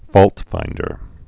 (fôltfīndər)